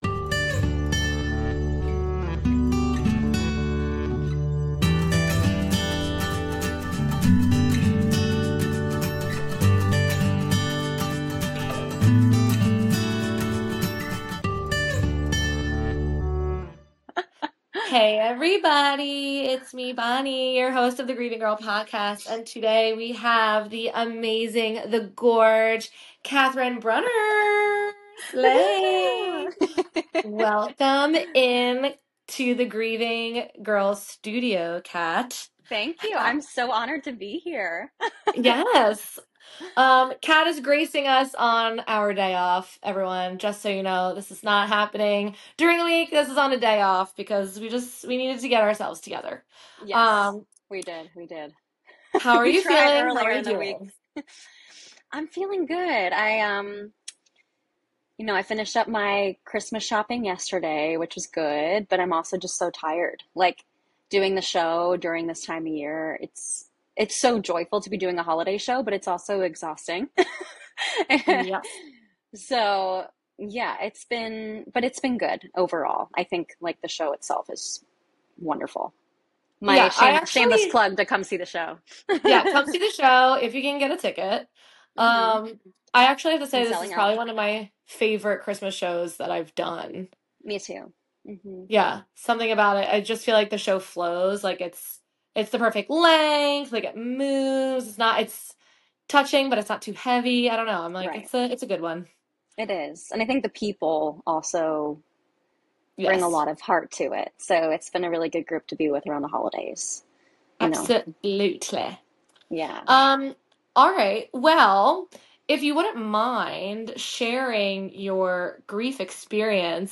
It’s an honest, heartfelt conversation about grief, love, and the connections that never really go away, even during the most joyful (and sometimes tough) time of year.